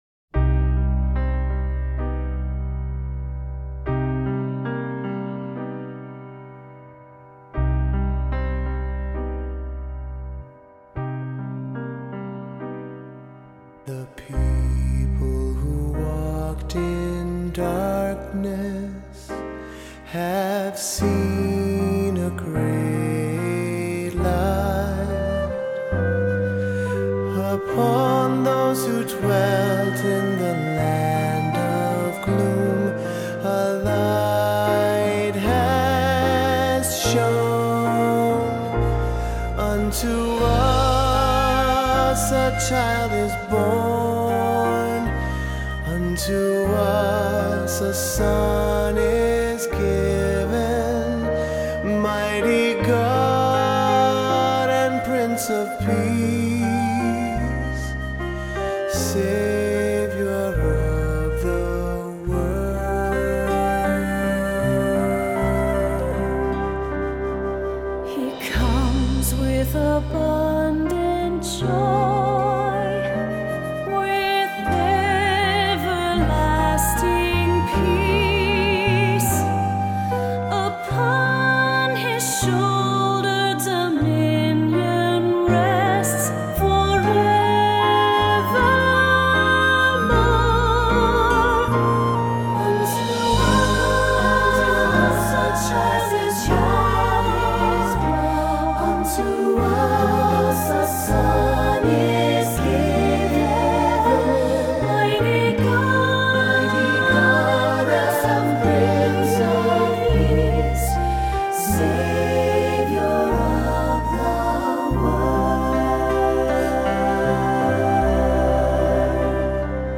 Voicing: SAB, cantor, assembly